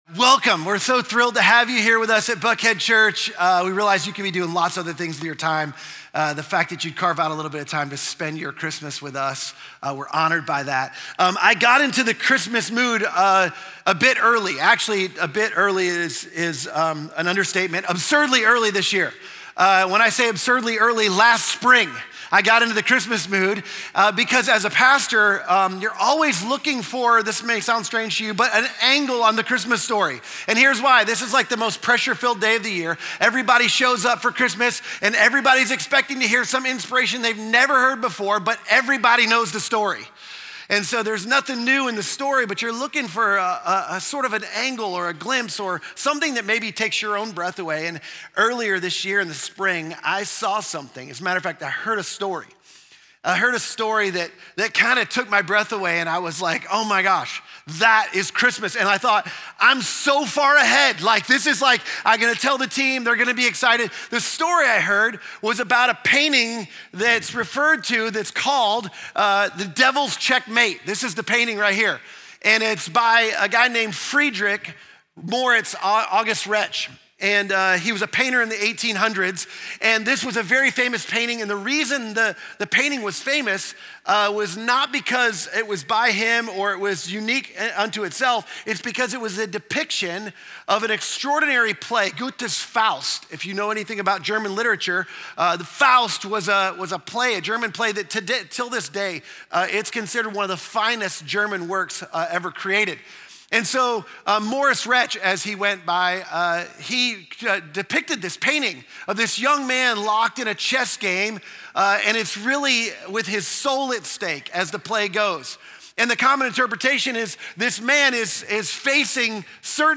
Christmas Eve at Buckhead Church 2025